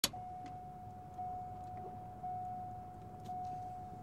Звуки заводящейся машины
Звук зуммера в автомобиле при запуске двигателя